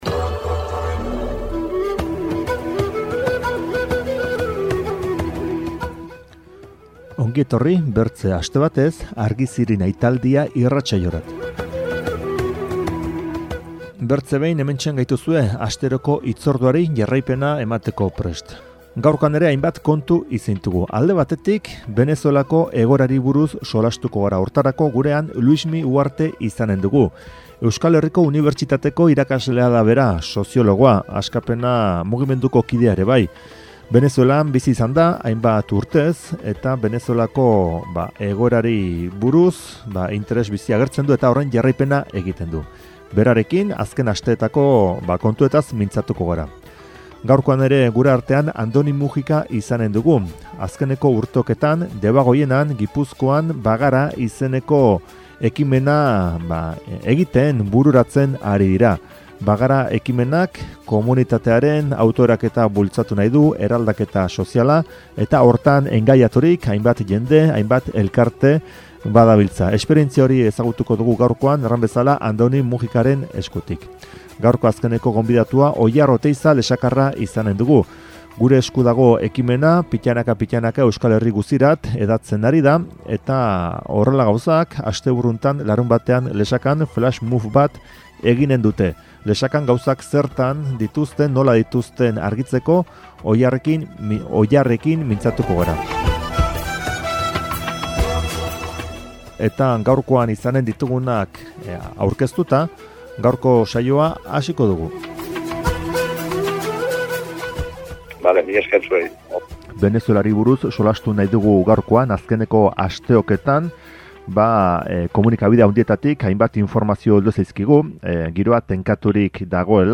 Elkarrizketekin batean hainbat kantu entzuteko aukera izanen dugu.